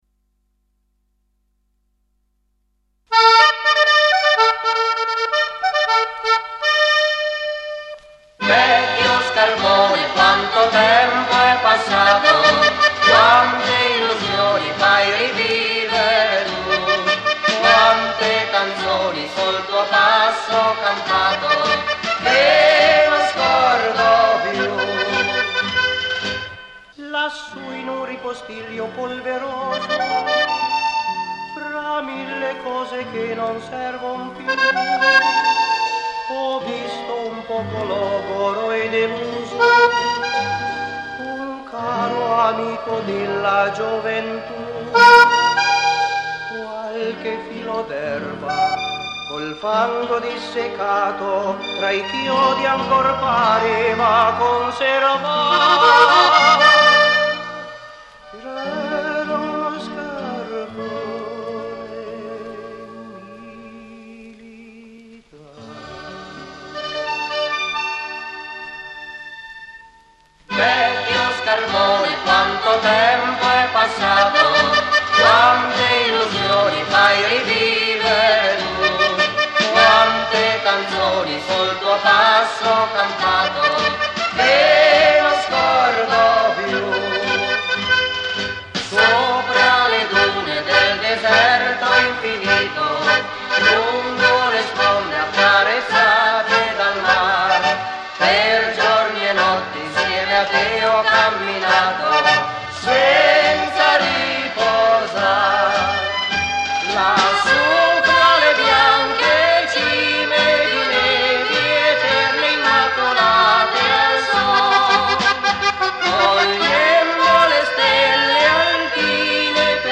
STEREO MONO R AT. 221